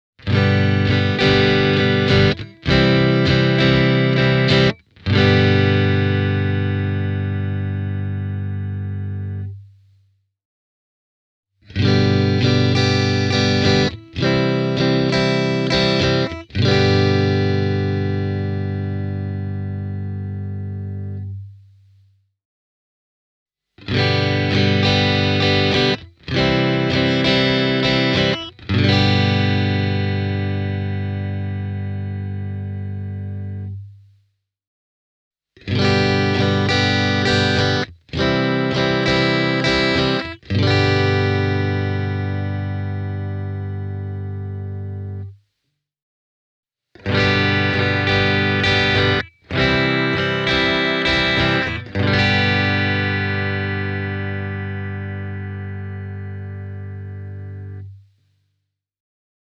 Tämän kitaran puhtaat soundit ovat mukavalla tavalla kuulaita ja raikkaita, ja myös yksikelaisissa löytyy mukavasti substanssia ja tukevuutta.
Tämä (suoraan AmPlugista äänitetty) klippi alkaa kaulamikrofonista: